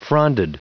Prononciation du mot fronded en anglais (fichier audio)